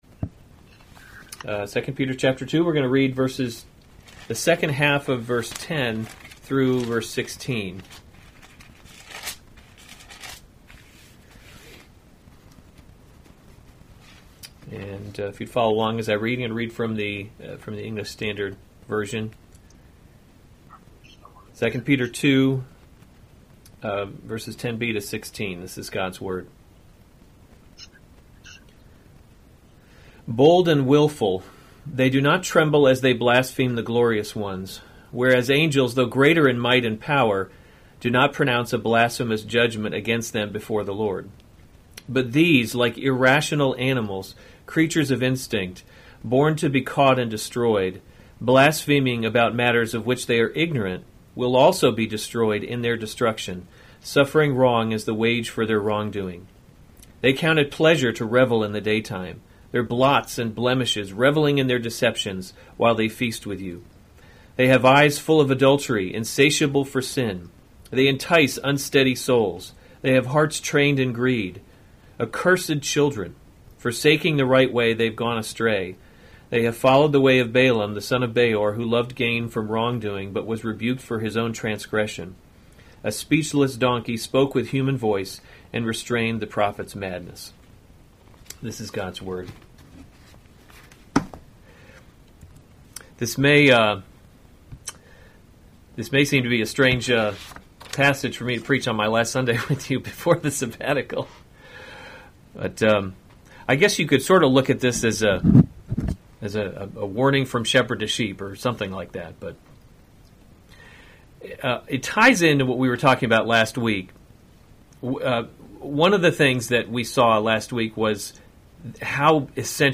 April 25, 2020 2 Peter – Covenant Living series Weekly Sunday Service Save/Download this sermon 2 Peter 2:10b-16 Other sermons from 2 Peter 10 and especially those who indulge in the […]